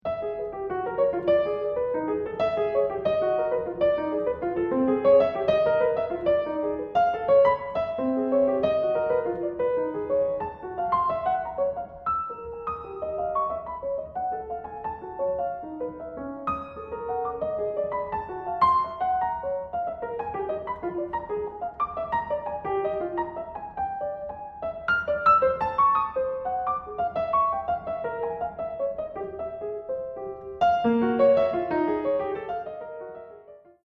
new music for piano